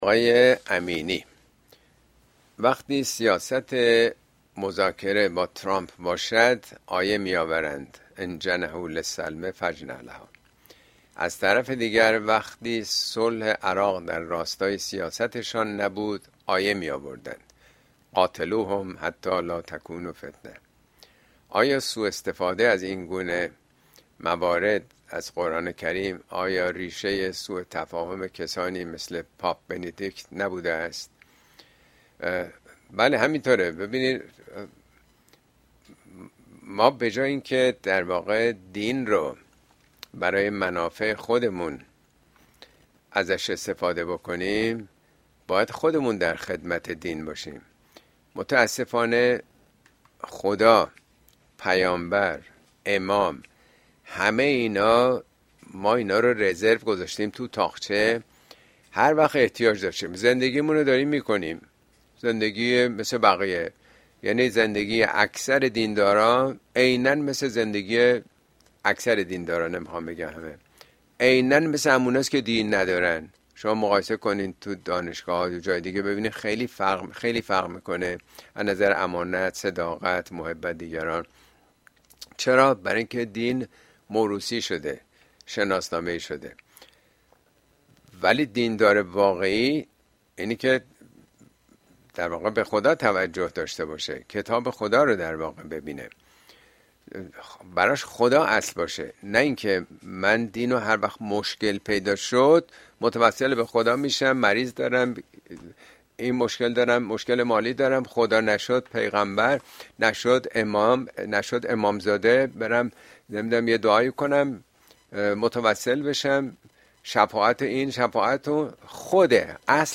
` مفاهيم اسلام شناسى قهر و آشتی با دین! اين سخنرانى به تاريخ ۲۳ آپریل ۲۰۲۵ در كلاس آنلاين پخش شده است توصيه ميشود براىاستماع سخنرانى از گزينه STREAM استفاده كنيد.